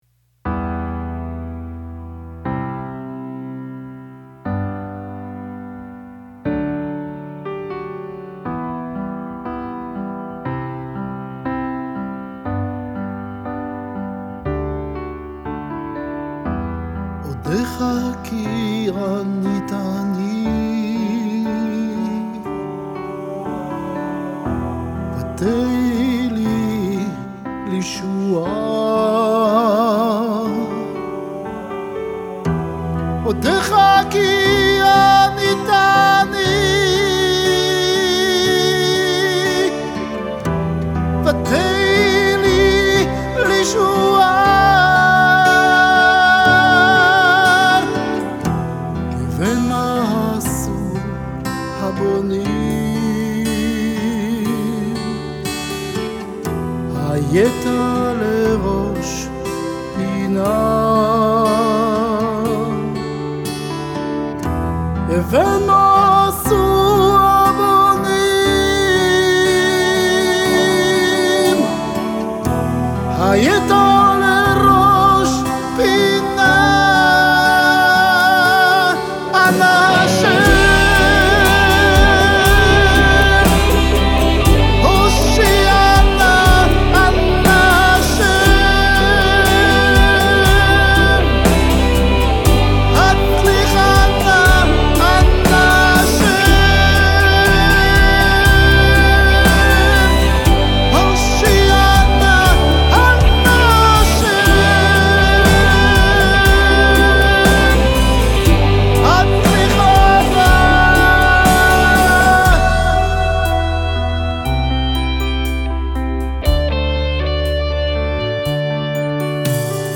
בפסנתר ובקלידים
בקלרינט
בגיטרה מזרחית